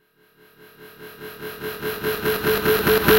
VEC3 FX Alarm 03.wav